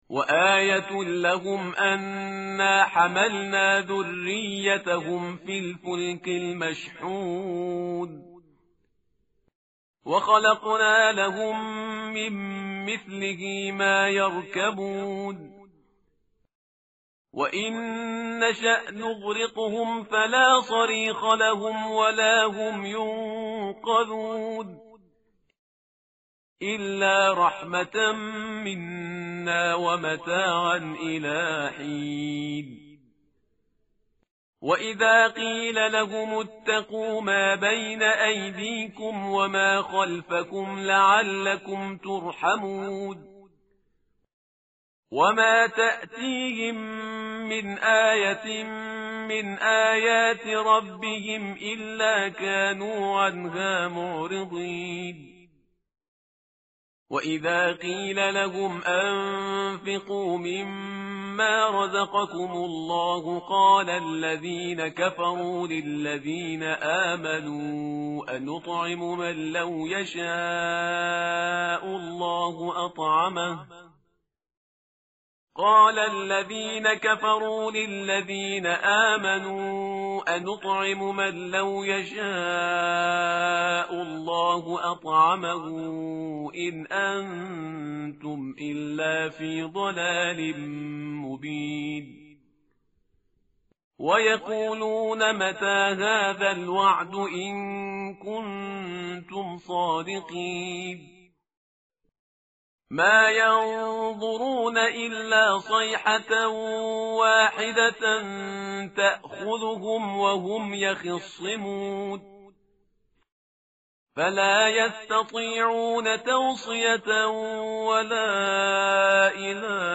متن قرآن همراه باتلاوت قرآن و ترجمه
tartil_parhizgar_page_443.mp3